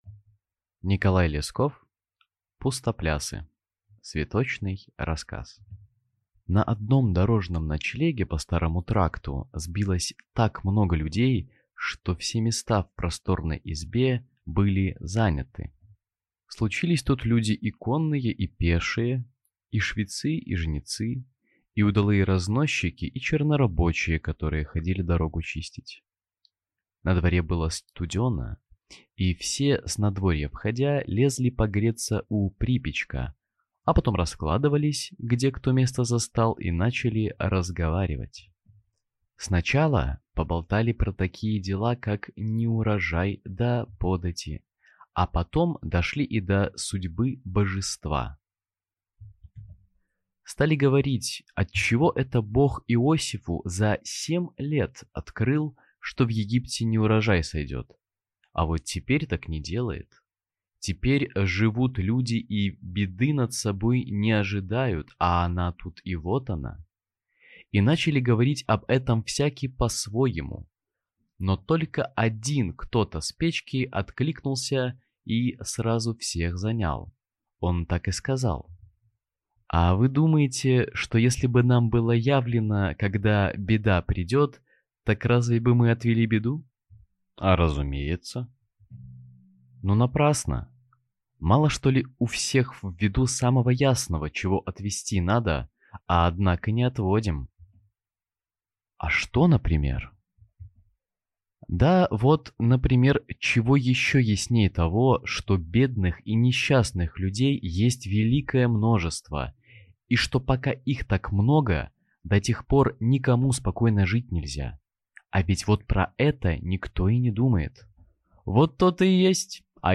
Аудиокнига Пустоплясы | Библиотека аудиокниг